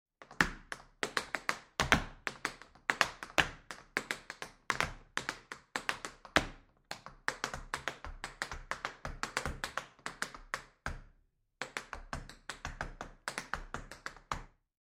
Звуки чечётки
Звук, в котором танцор осваивает чечётку